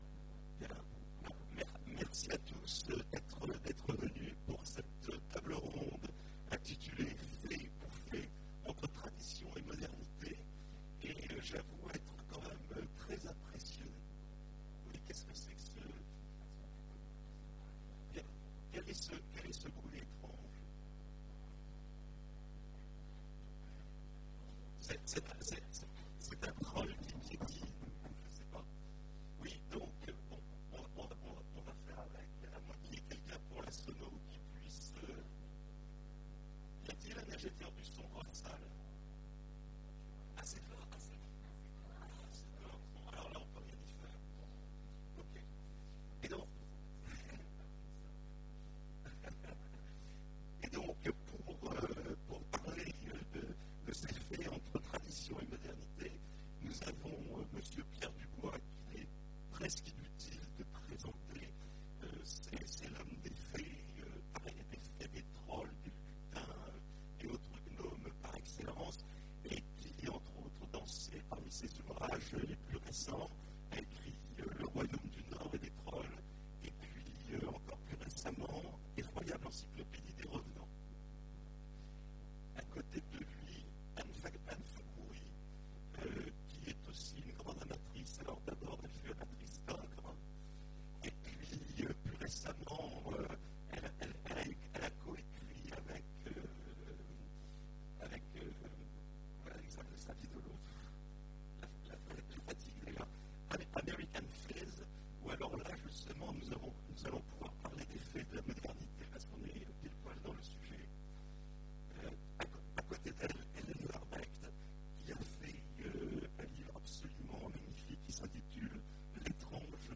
Imaginales 2015 : Conférence Fées ou fays